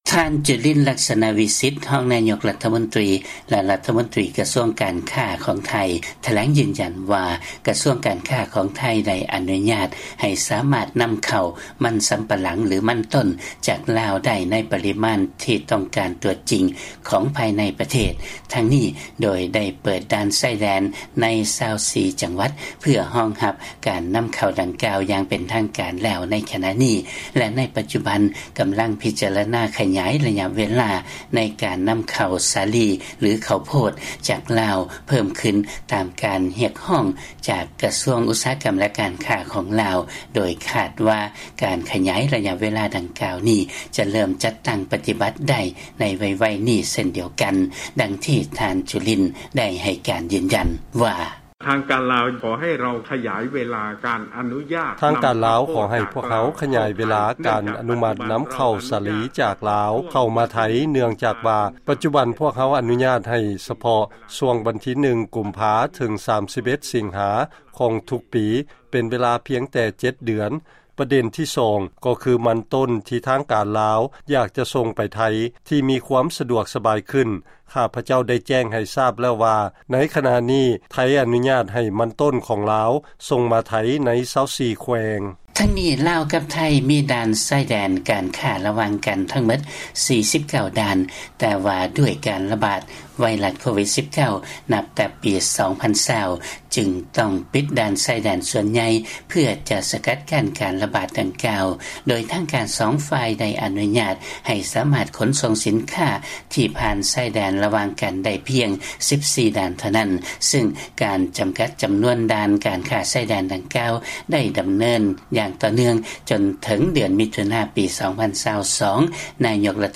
ຟັງລາຍງານ ລັດຖະມົນຕີໄທ ເວົ້າວ່າ ໄດ້ເປີດດ່ານ 24 ແຫ່ງ ທີ່ອະນຸຍາດໃຫ້ນຳເຂົ້າສິນຄ້າກະສິກຳຈາກລາວ